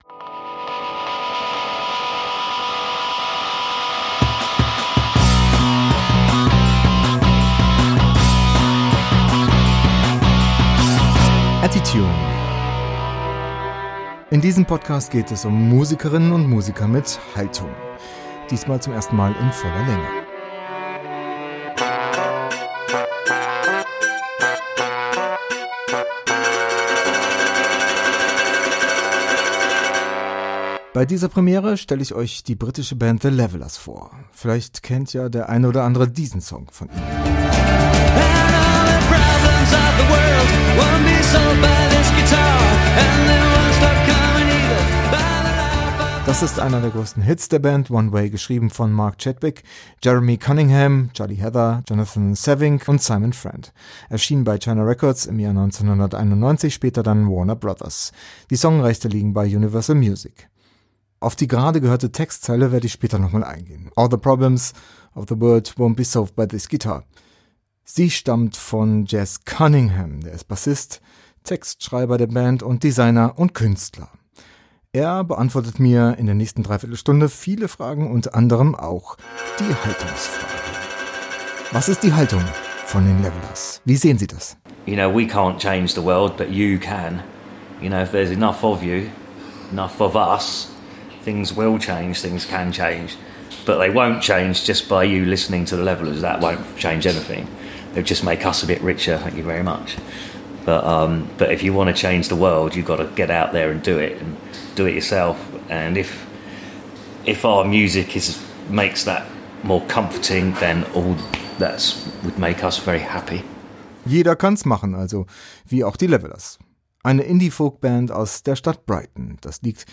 Die Band gibt es seit 30 Jahren schon, zu diesem Jubiläum waren sie auf Tour auch in München, wo ich den Bassisten und das Gründungsmitglied der Band Jeremy "Jez" Cunningham interviewen durfte.